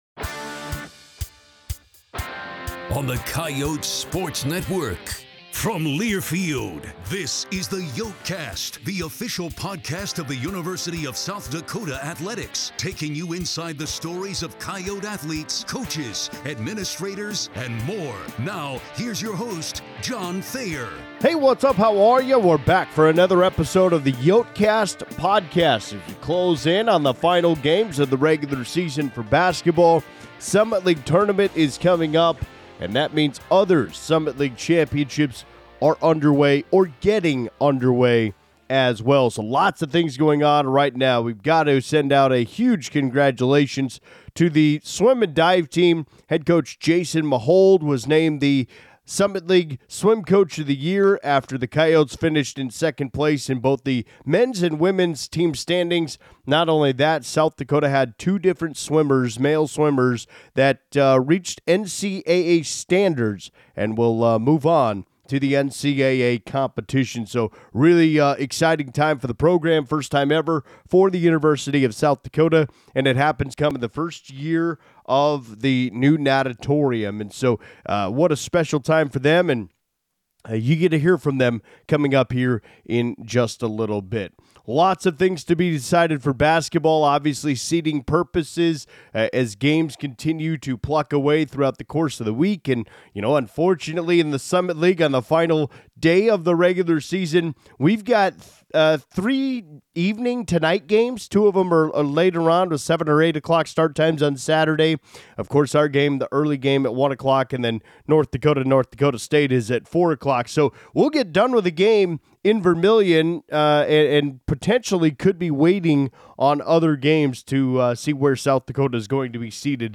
In a combined interview